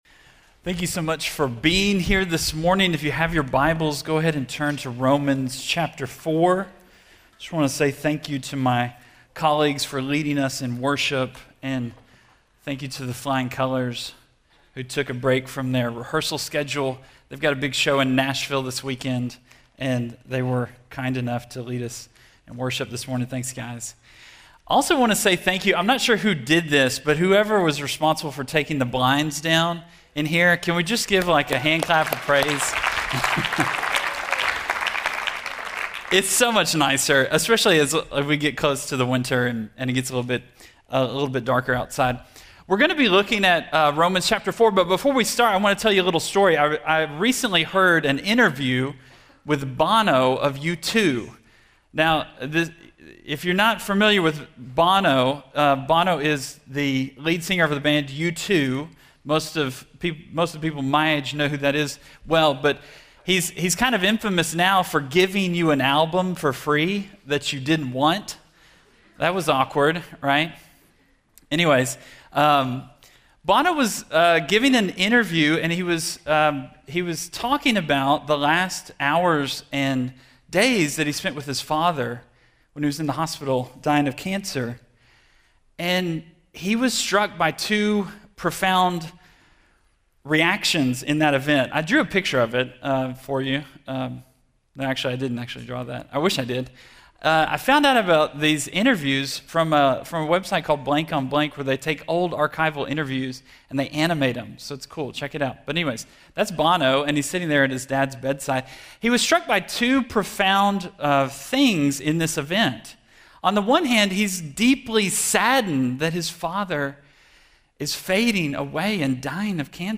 Chapels